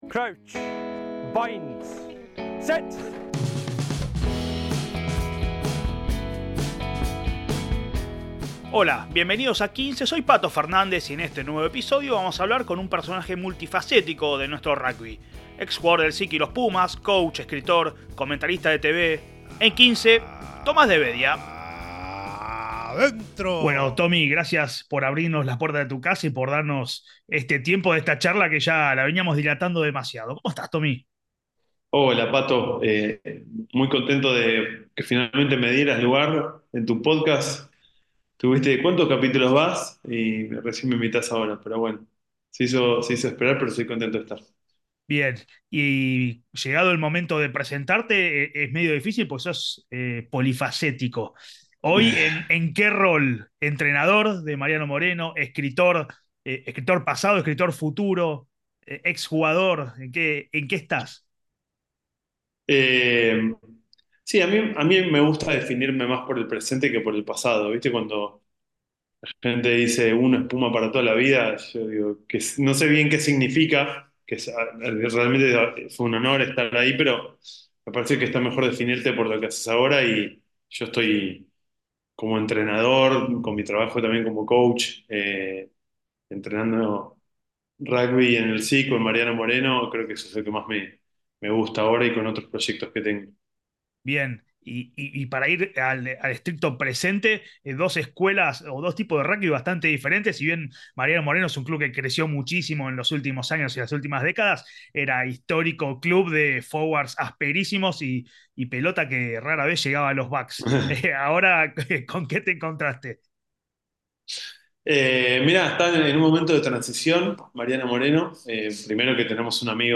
¡Charlas de rugby con los protaginistas!